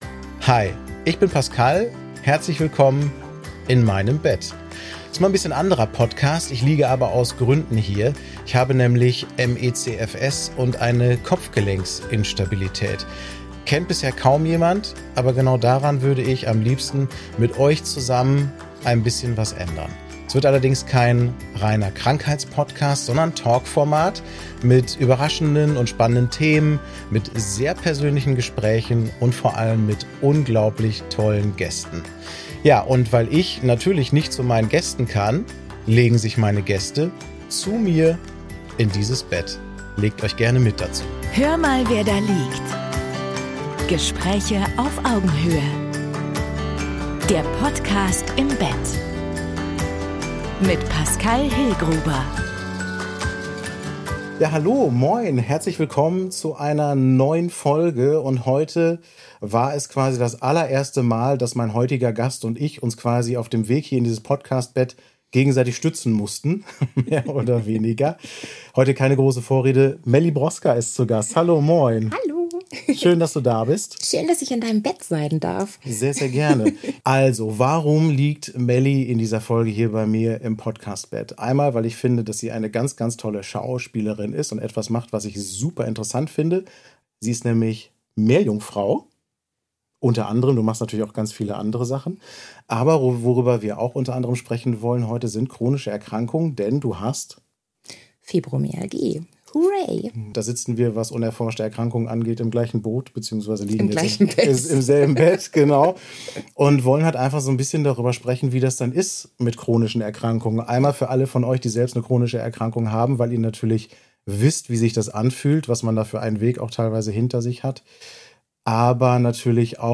In dieser Folge sprechen wir offen über Schmerz, Schauspielerei und Selbstbehauptung – und darüber, warum wir nicht einfach mal krank sein dürfen, sondern immer funktionieren sollen. Ein ernstes, aber auch humorvolles Gespräch zweier chronisch Kranker mit Haltung.